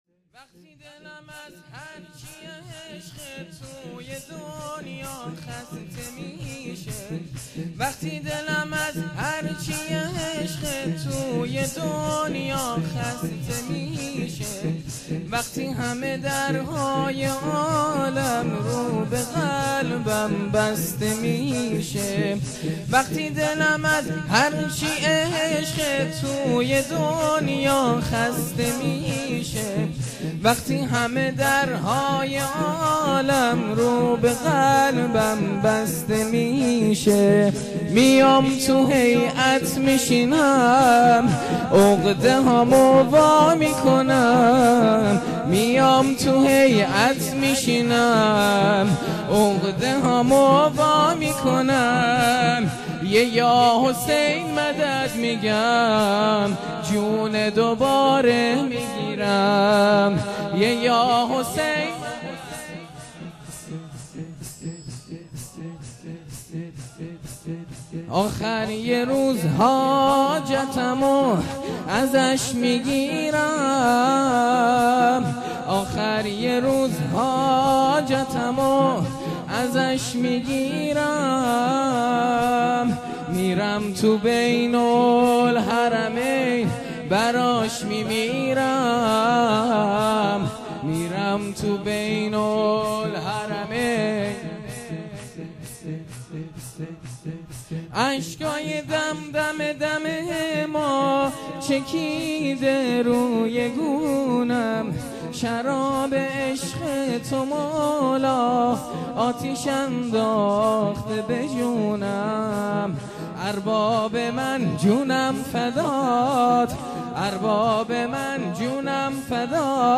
جلسه هفتگی ۱۱ مهر ماه ۱۳۹۸